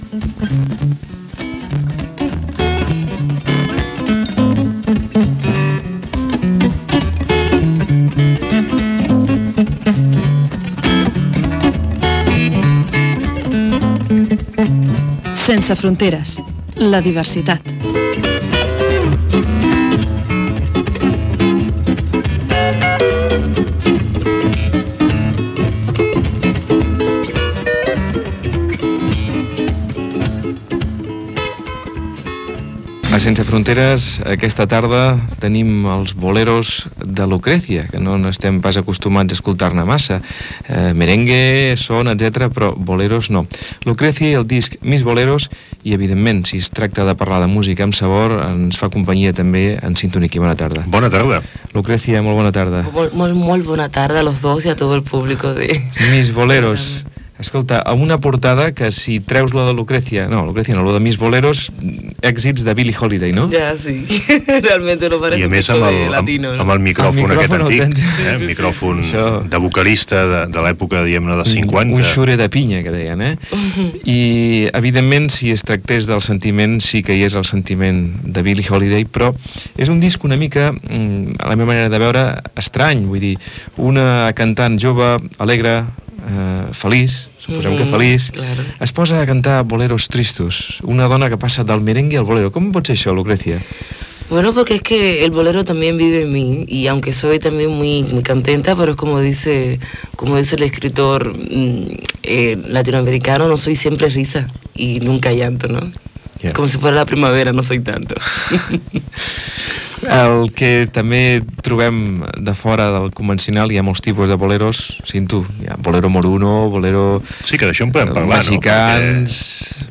Entrevista a Lucrecia pel disc "Mis boleros".
Musical